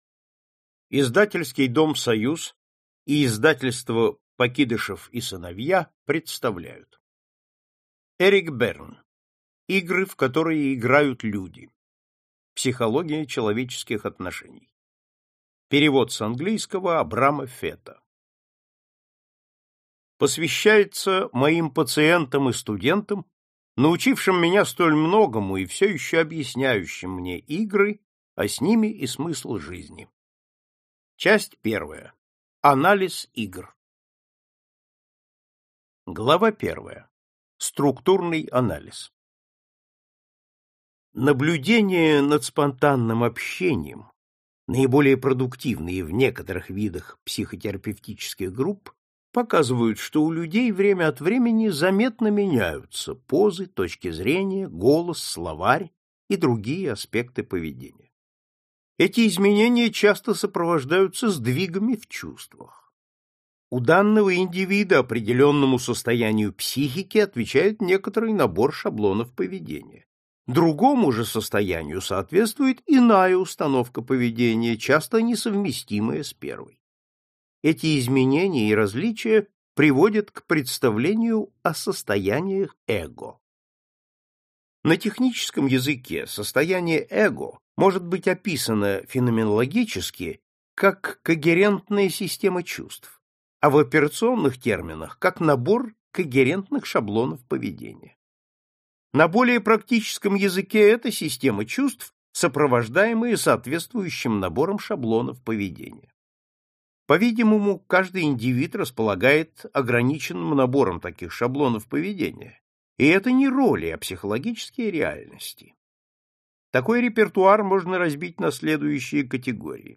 Аудиокнига Игры, в которые играют люди. Психология человеческих взаимоотношений - купить, скачать и слушать онлайн | КнигоПоиск